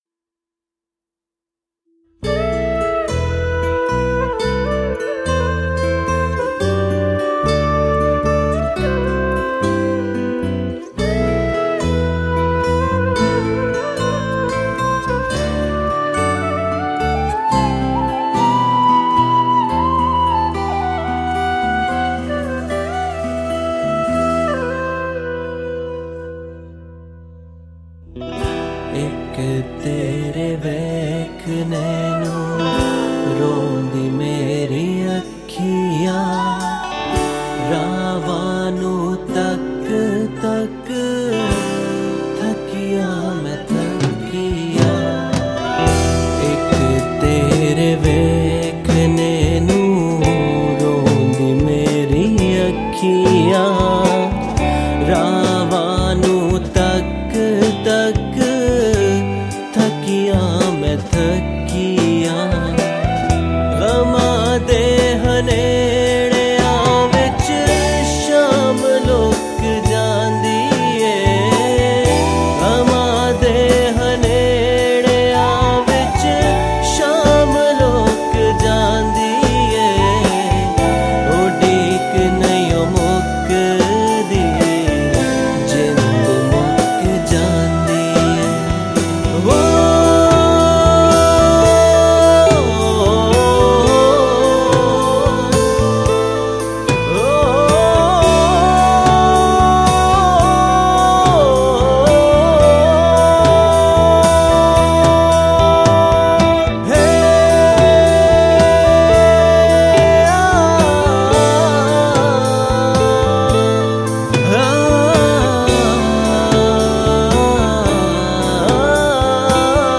Pakistani Songs